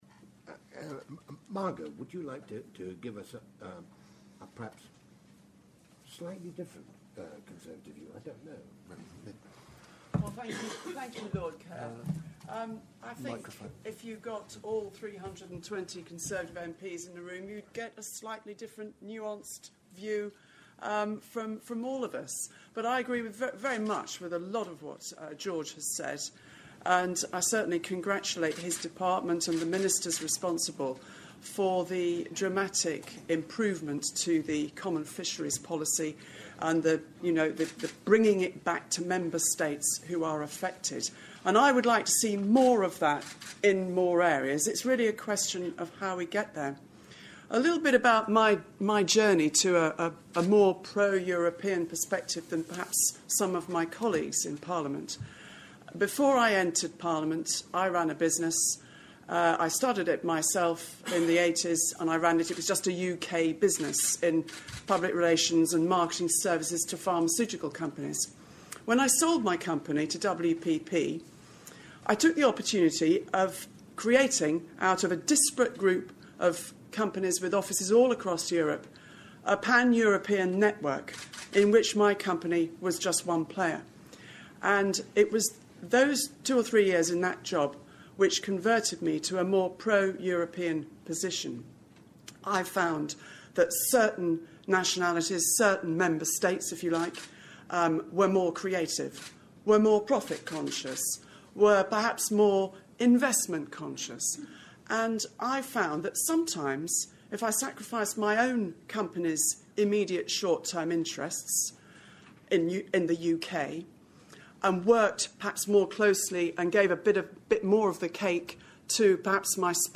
Part 2: Panellist discussions at launch of 'How to build a modern EU', 5 March 2014